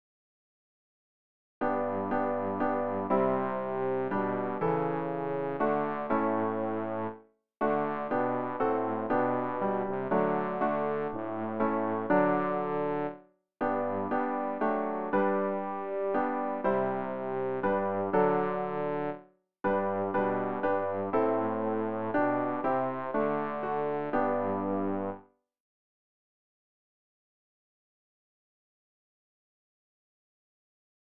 rg-790-ach-bleib-bei-uns-bass.mp3